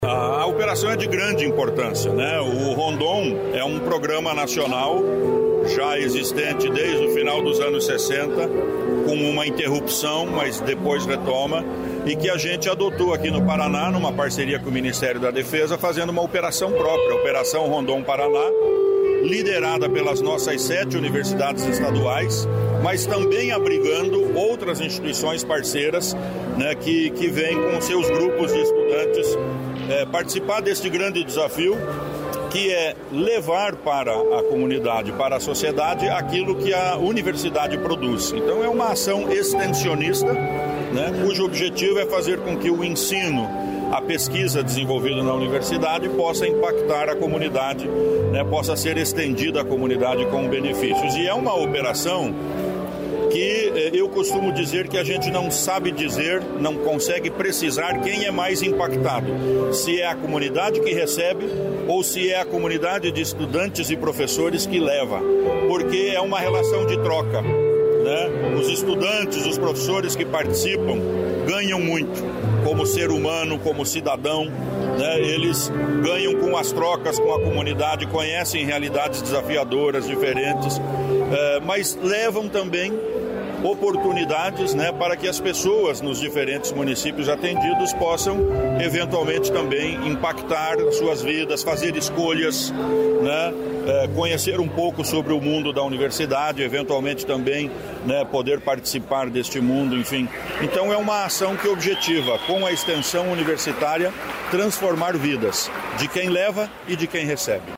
Sonora do secretário da Ciência, Tecnologia e Ensino Superior do Estado do Paraná, Aldo Bona, sobre Operação Rondon Paraná